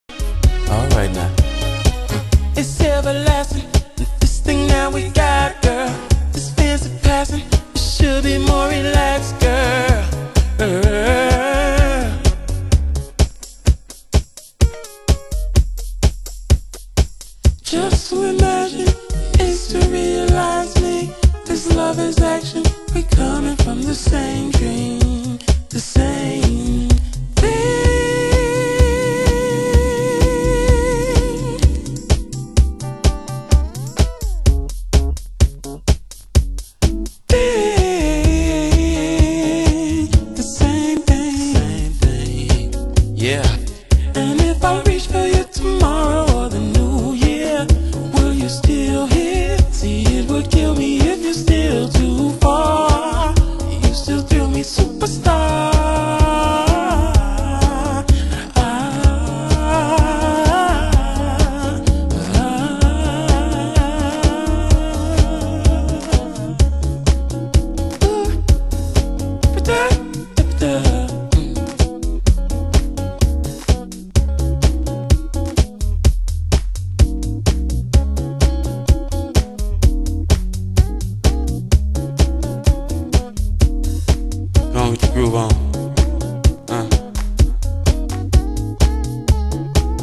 盤質：A1イントロに線の傷ノイズ 　　ジャケ：少しスレ、取り出し口にヨレ